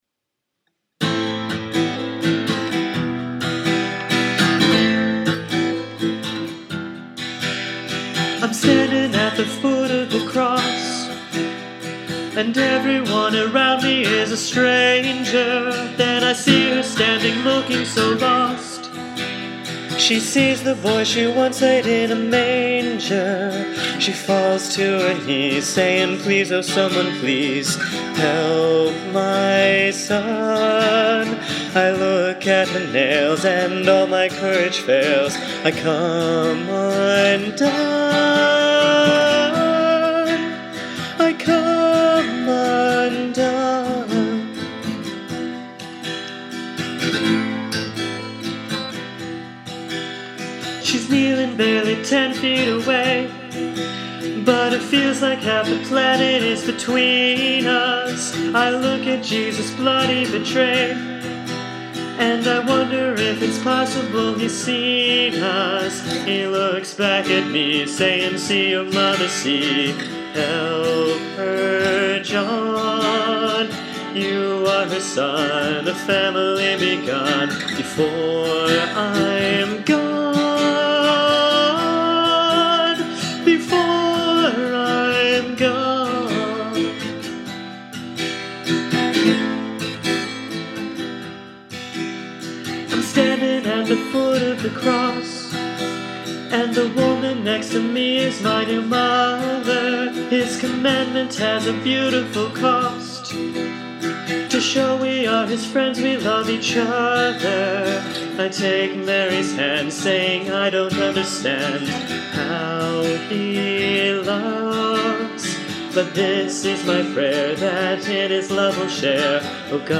For each song, I gave myself no more than two hours to write and record it. These are by no means polished songs; they are the responses of my heart to Christ crucified.